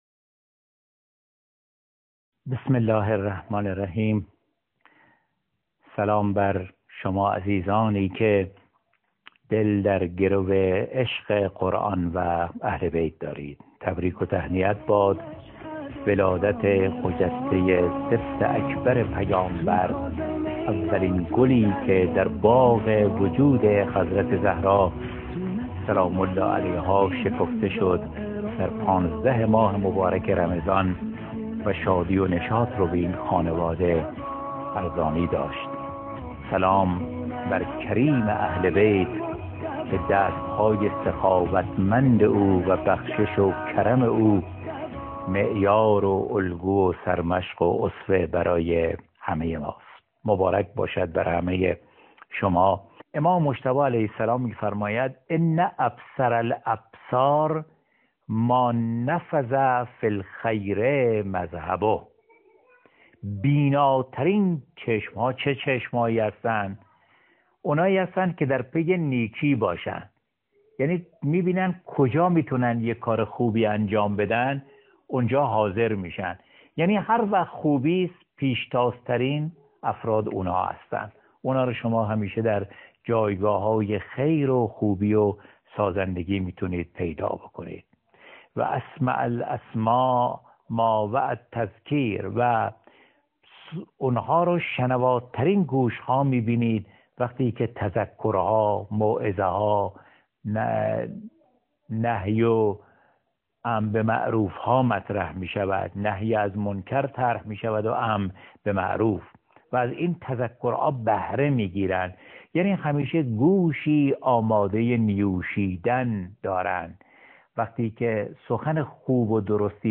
قطعه صوتی زیر بخشی از سخنرانی